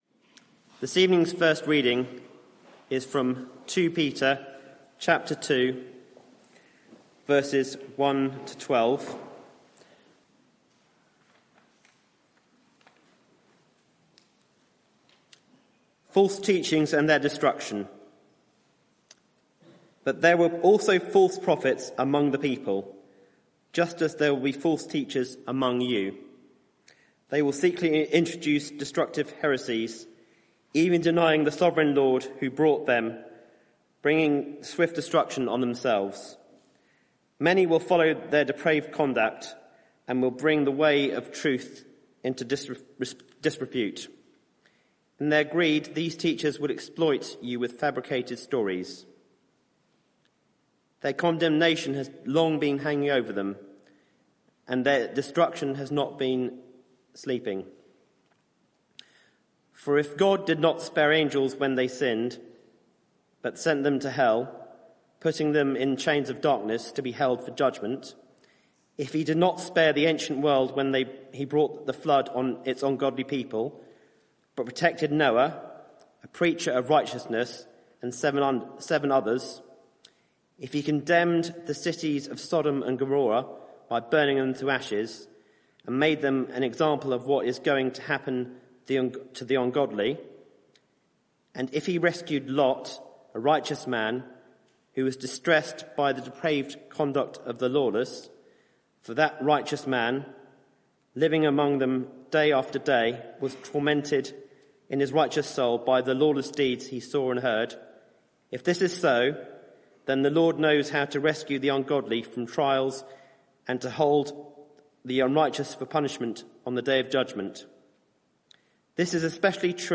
Media for 6:30pm Service on Sun 15th Sep 2024 18:30 Speaker
Passage: 2 Peter 2:1-22 Series: Stable and growing Theme: Sermon (audio) Search the media library There are recordings here going back several years.